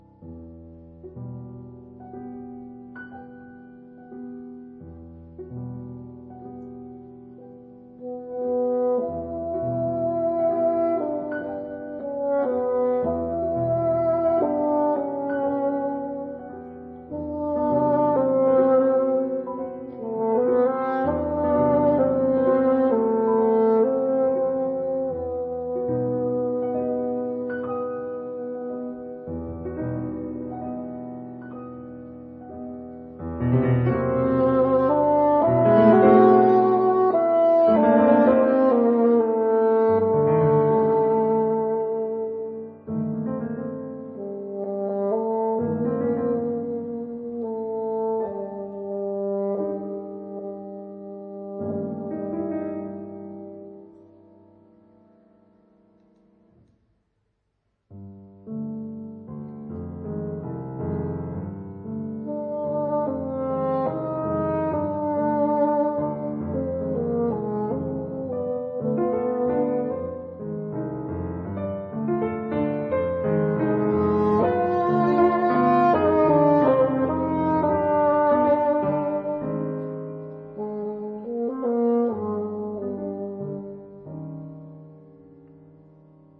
特別是，音色上的控制，巴黎音樂院的木管傳統，的確是強。